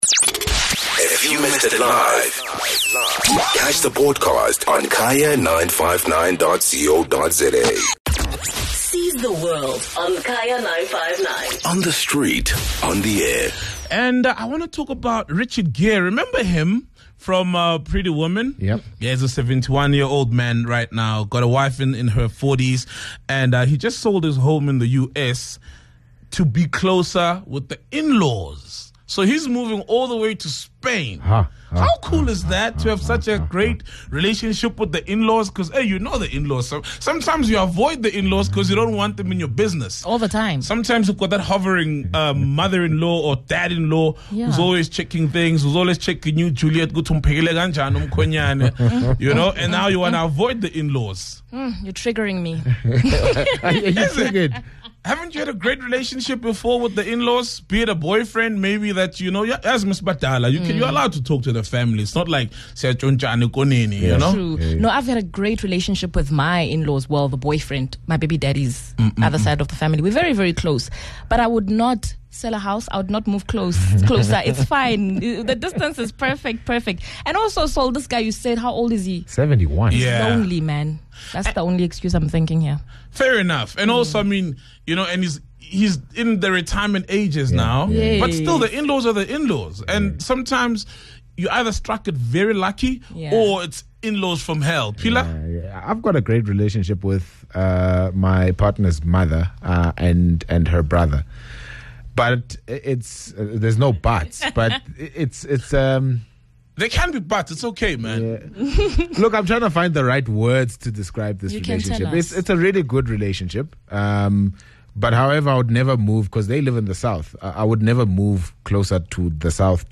The team and listeners discussed the bittersweet relationships they have with their in-laws.